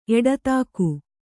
♪ eḍatāku